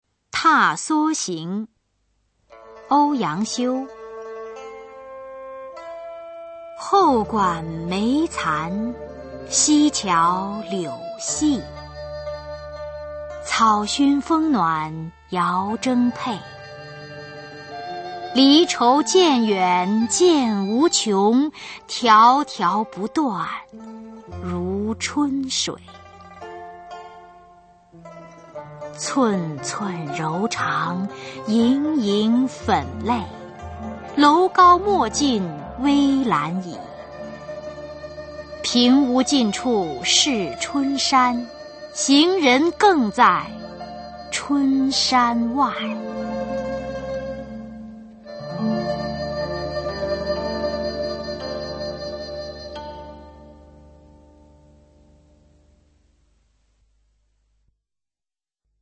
[宋代诗词朗诵]欧阳修-踏莎行 古诗词诵读